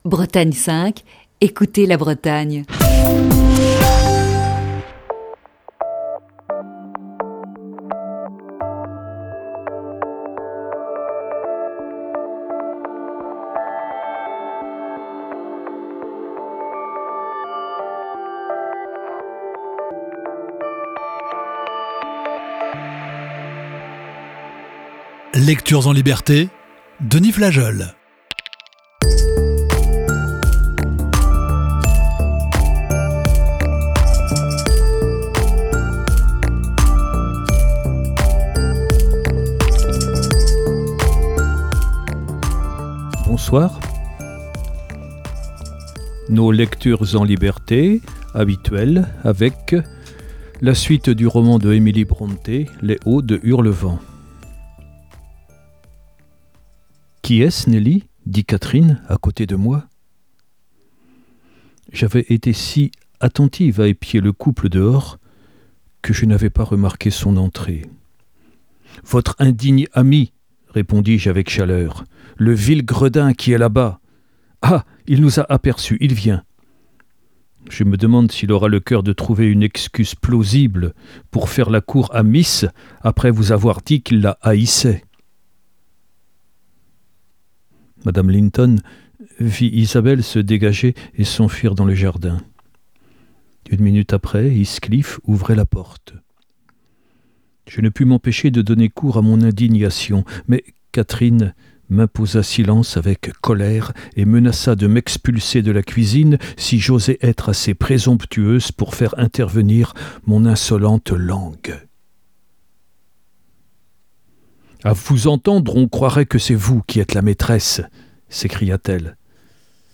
lit le célèbre roman d'Emily Brontë "Les Hauts de Hurlevent".